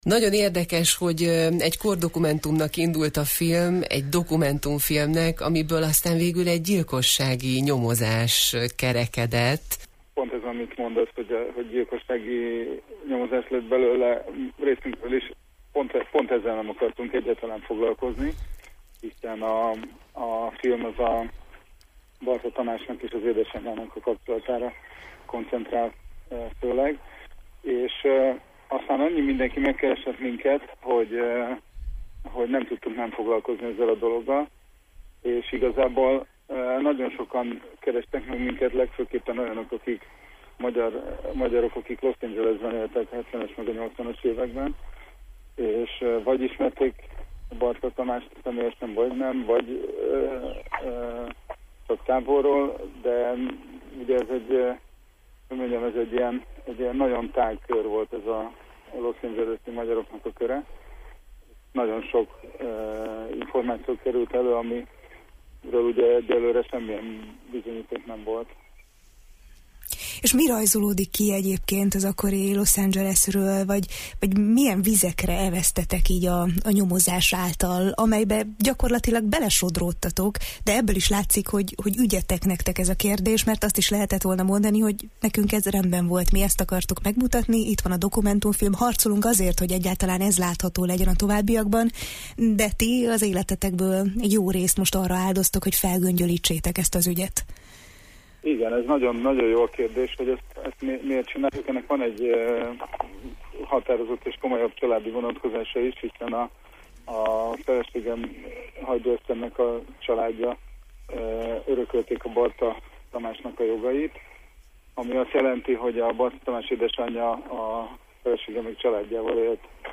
a Jó reggelt, Erdély!-ben beszélgettünk: